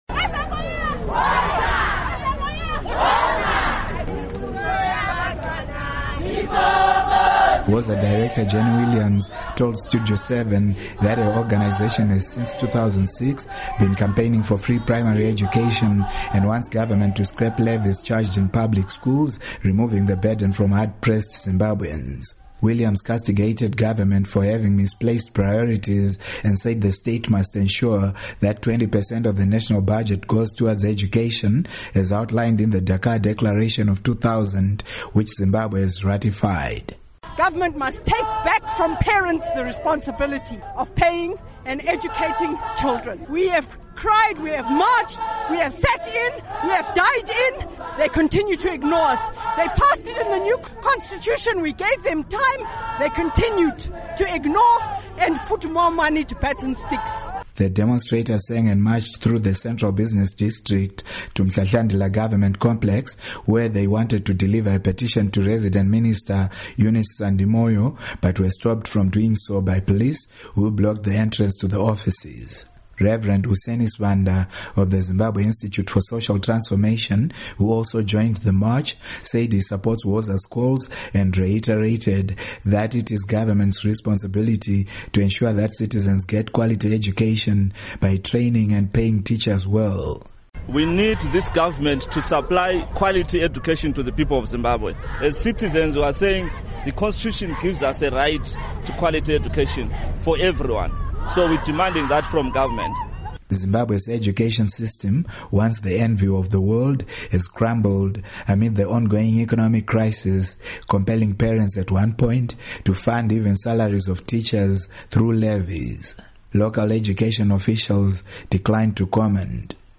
Report on WOZA demonstration